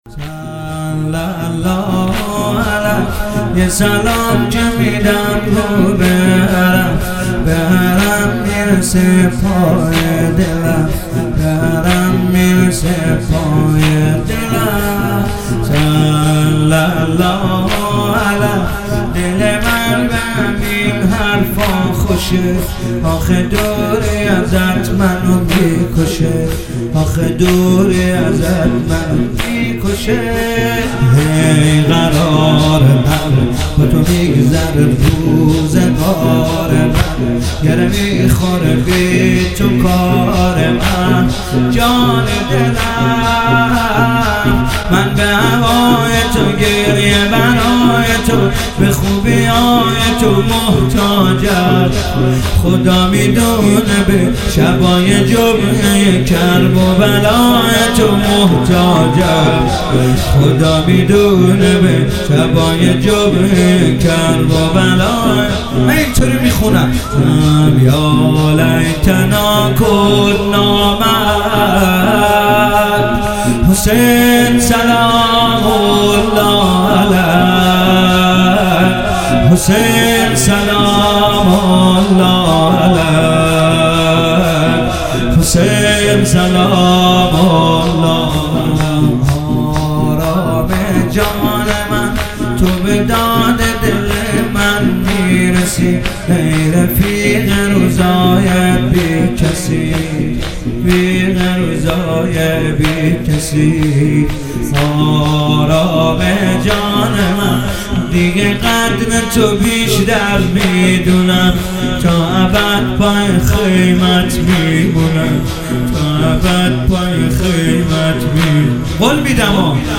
مداحی حسین طاهری |محرم الحرام 1442| هیئت ام المصائب (س) بابل | پلان 3